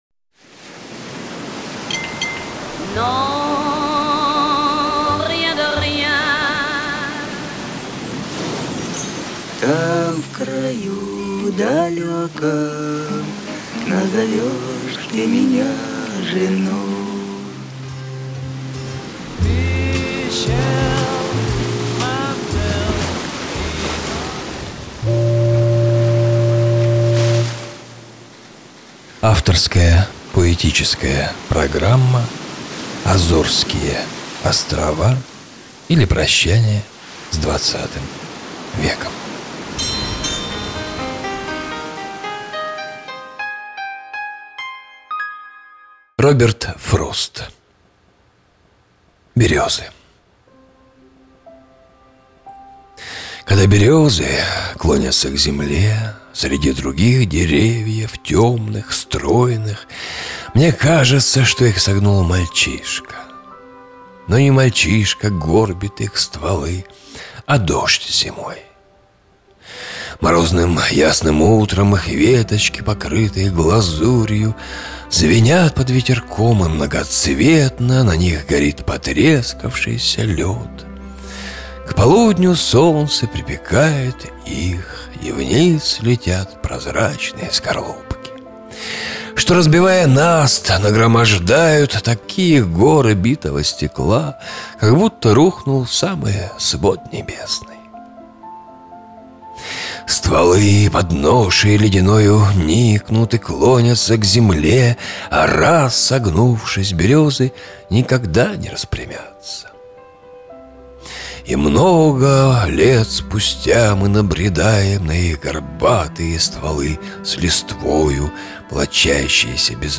На протяжении достаточно долгого времени, начиная с 1999 года, на разных радиостанциях города* выходили мои авторские поэтические и литературно-музыкальные программы – «АЗОРСКИЕ ОСТРОВА, ИЛИ ПРОЩАНИЕ С ХХ ВЕКОМ…», «ЖАЖДА НАД РУЧЬЁМ», «НА СОН ГРЯДУЩИЙ», «ПолУночный КОВБОЙ», «ПОСЛУШАЙТЕ!».